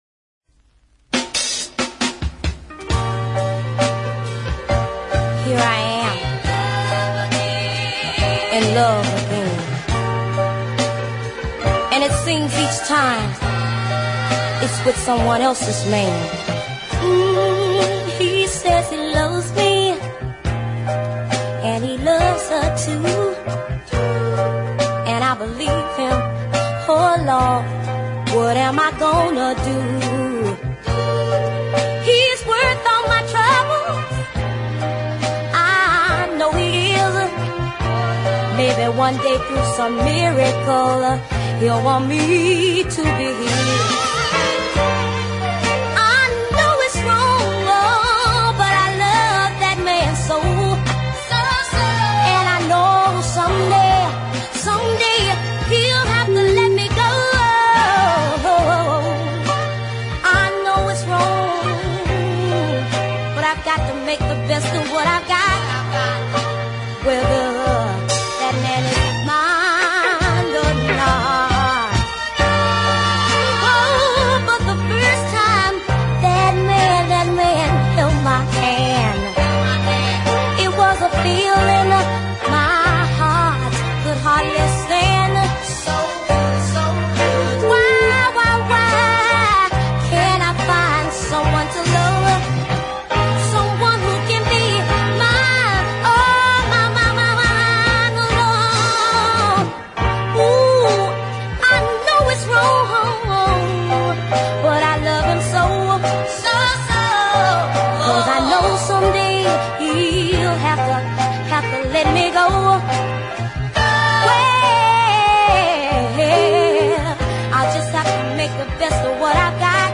is a heartfelt ballad